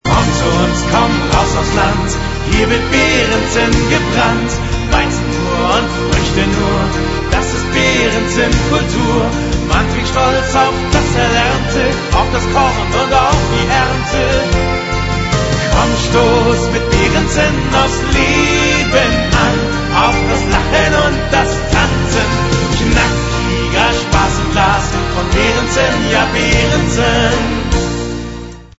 ausgelassene und fr�hliche Stimmung